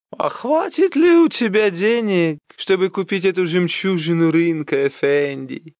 Образцы озвучания, прошедшие визирование у Супера и допущенные к опубликованию: